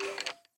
minecraft / sounds / mob / skeleton / say3.ogg